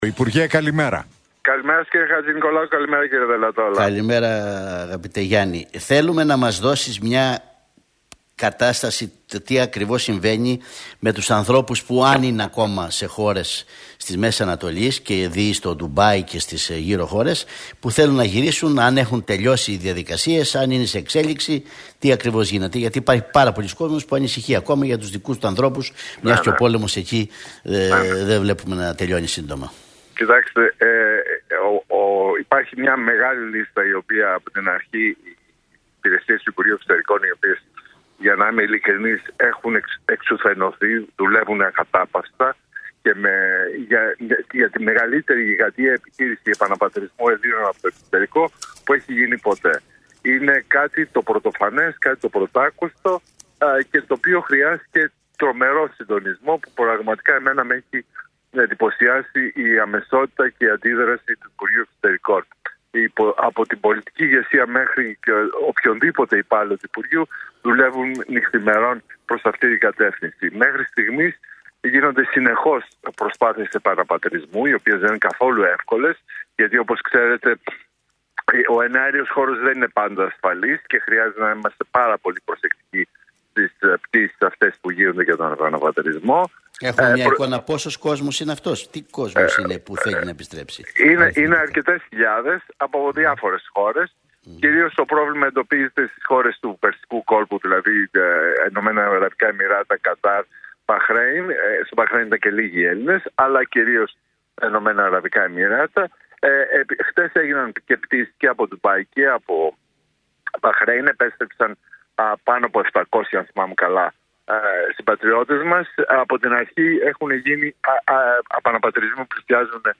Για τον πόλεμο στη Μέση Ανατολή και τις επιχειρήσεις επαναπατρισμού, μίλησε, μεταξύ άλλων ο υφυπουργός Εξωτερικών αρμόδιος για θέματα απόδημου ελληνισμού Γιάννης Λοβέρδος, μιλώντας στον Realfm 97,8 και την εκπομπή του Νίκου Χατζηνικολάου